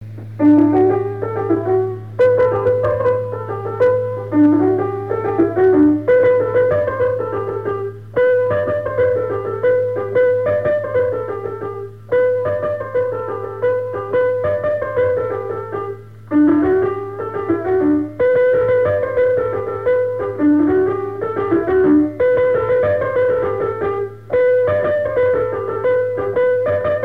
danse : branle : avant-deux
Trois avant-deux au piano
Pièce musicale inédite